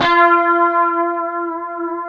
F#4 HSTRT VB.wav